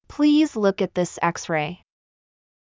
ﾌﾟﾘｰｽﾞ ﾙｯｸ ｱｯﾄ ﾃﾞｨｽ ｴｯｸｽﾚｲ